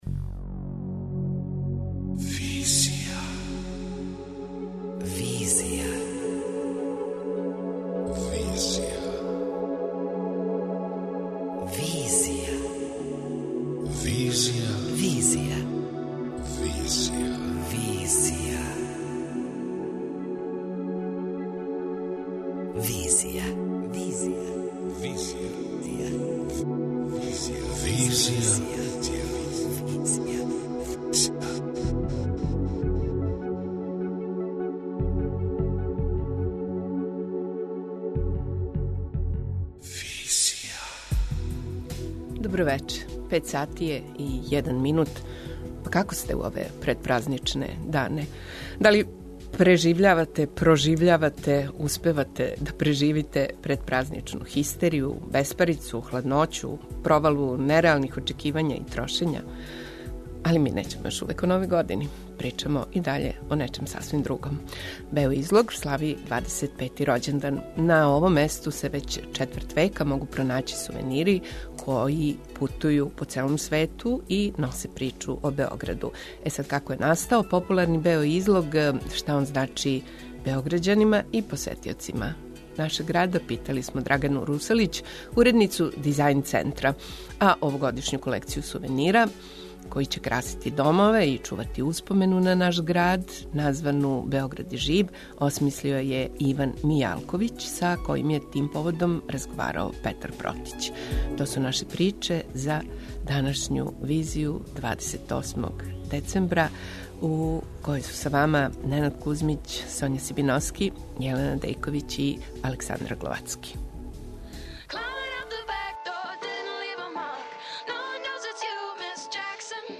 преузми : 27.07 MB Визија Autor: Београд 202 Социо-културолошки магазин, који прати савремене друштвене феномене.